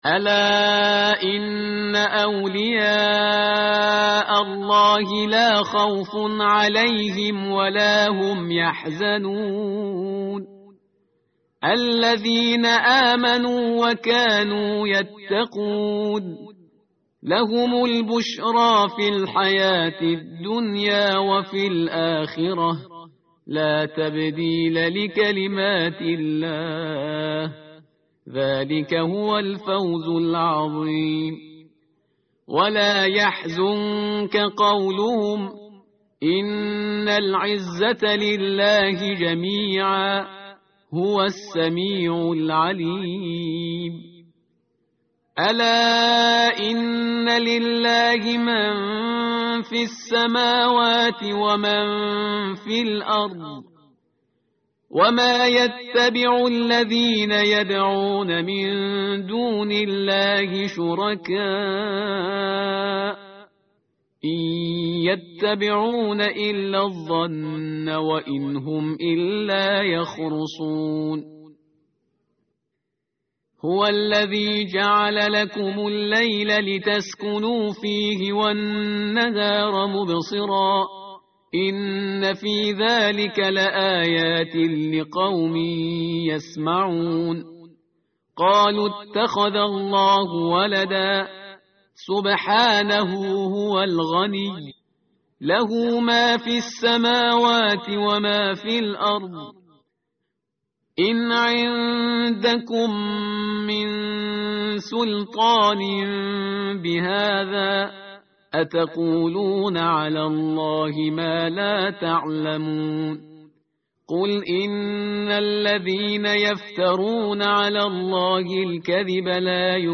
tartil_parhizgar_page_216.mp3